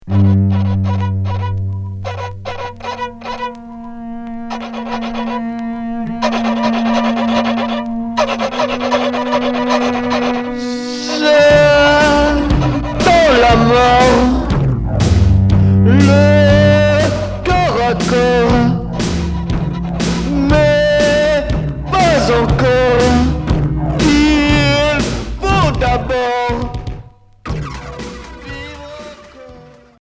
Scato trash expérimental